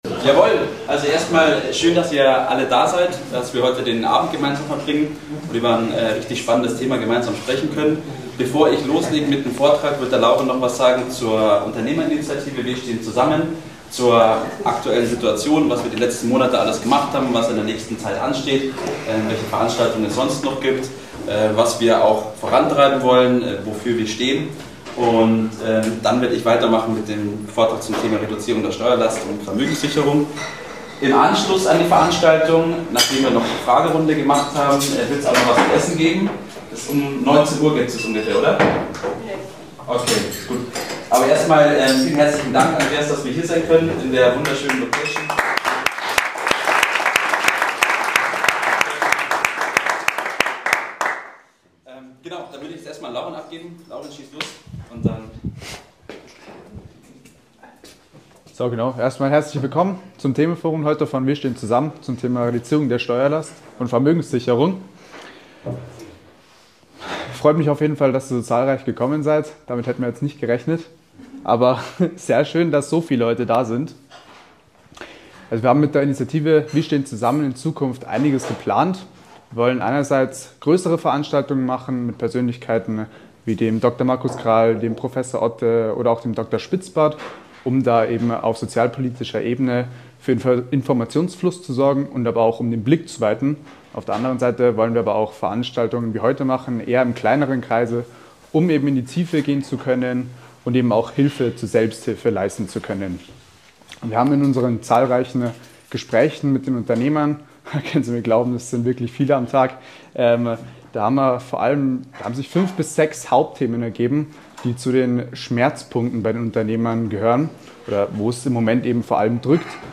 Vortrag: Reduzierung der Steuerlast für Unternehmen ~ STEUERN zu VERMÖGEN machen Podcast